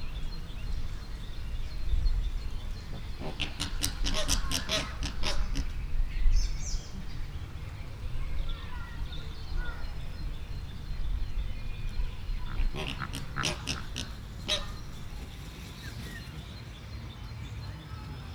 Directory Listing of /_MP3/allathangok/veszpremizoo2013_premium/rozsasflamingo_chileiflamingo/